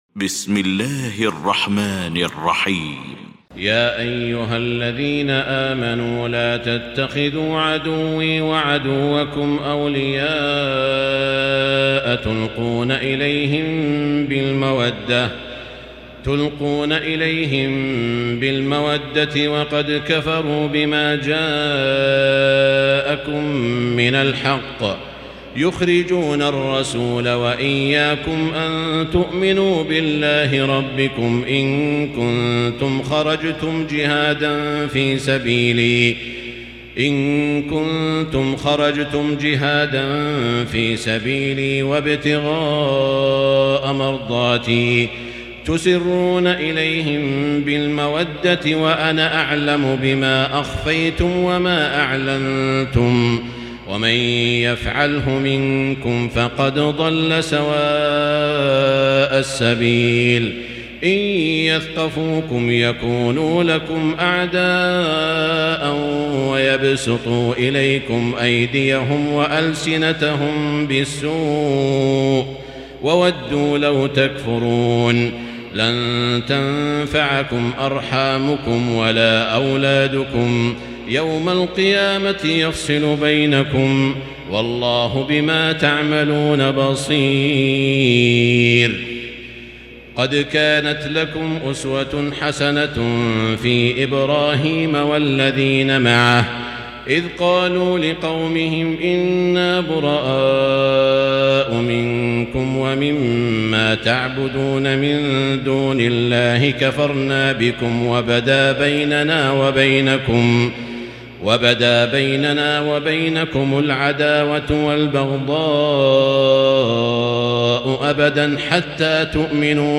المكان: المسجد الحرام الشيخ: سعود الشريم سعود الشريم الممتحنة